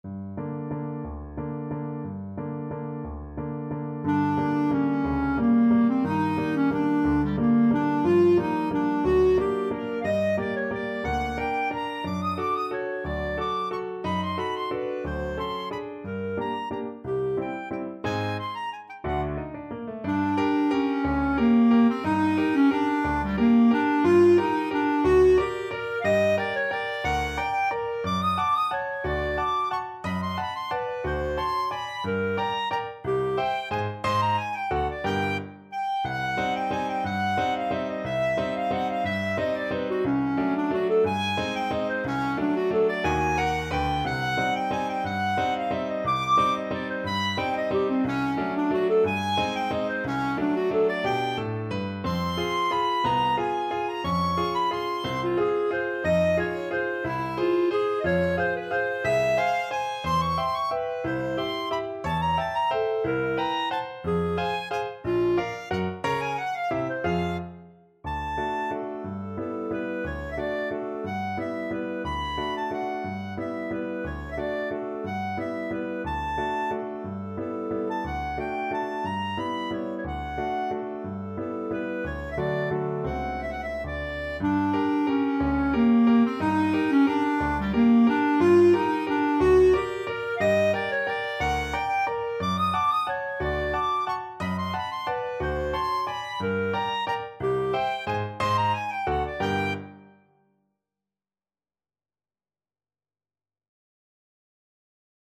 Allegro espressivo .=60 (View more music marked Allegro)
G4-Eb7
3/4 (View more 3/4 Music)
Classical (View more Classical Clarinet Music)